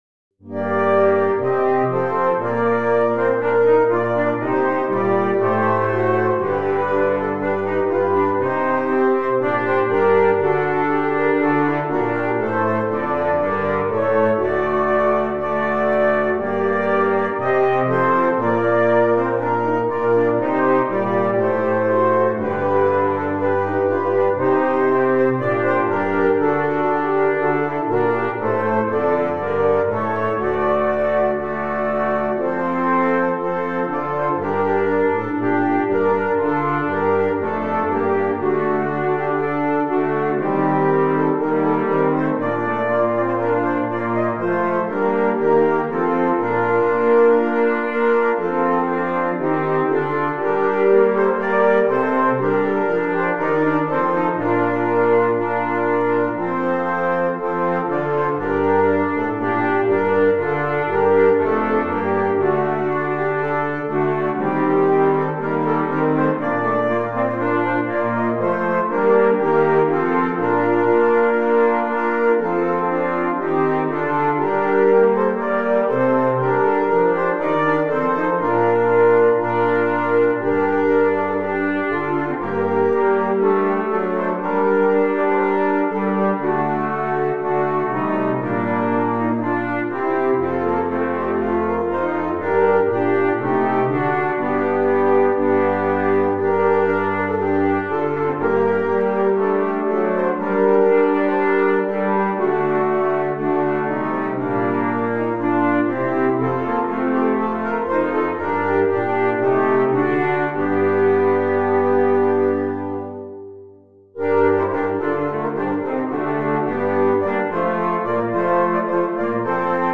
OBS: The original insturmentation is sadly not possible due to the fact that i dont have good enough virtual instruments that have a good sound, the only ones that i have are soundfonts that sound quite unrealistic and robotic...